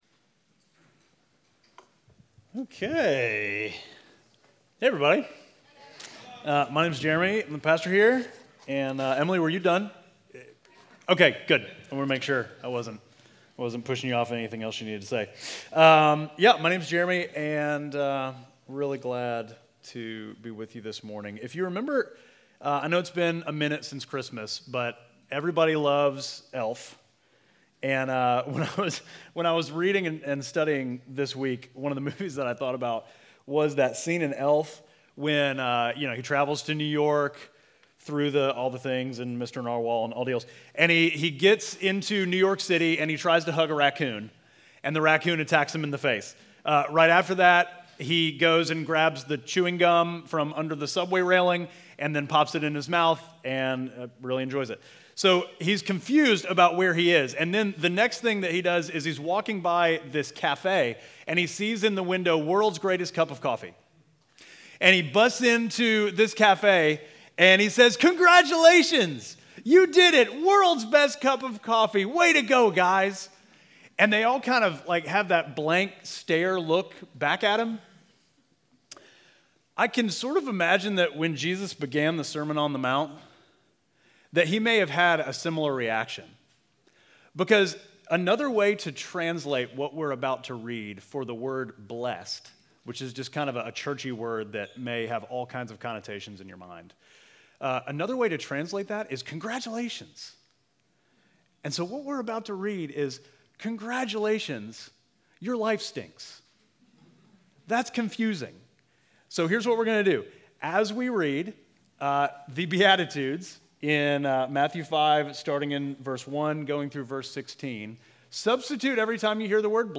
Midtown Fellowship Crieve Hall Sermons The Way Of The King: Blessedness Mar 03 2024 | 00:40:23 1x 00:00 / 00:40:23 Subscribe Share Apple Podcasts Spotify Overcast RSS Feed Share Link Embed